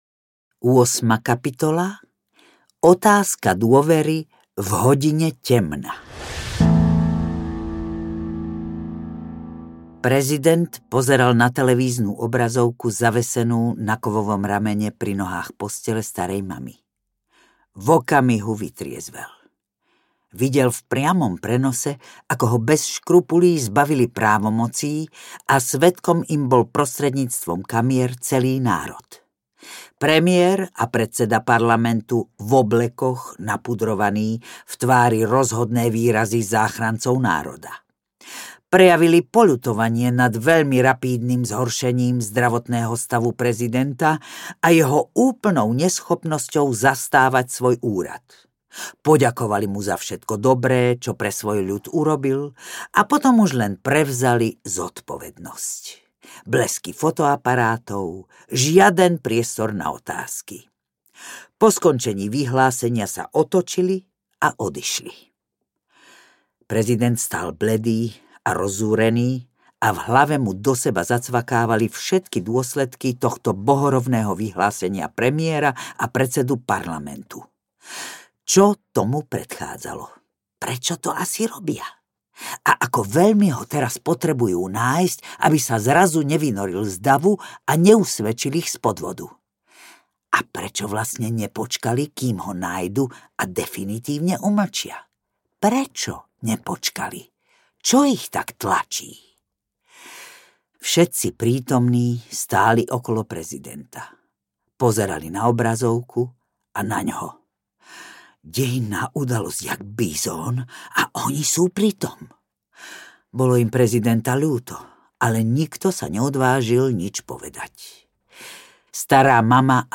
Zvláštne šťastie audiokniha
Ukázka z knihy
• InterpretZuzana Kronerová